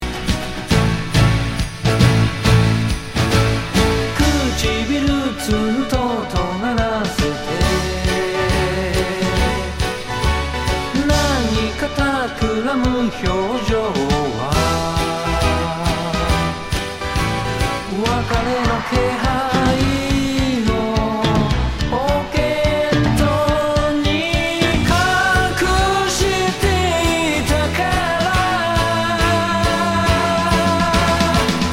Tag       OTHER ROCK/POPS/AOR